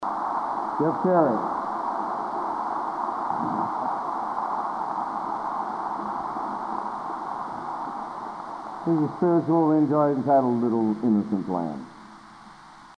Collection: Broadway Methodist, 1980
Genre: | Type: Director intros, emceeing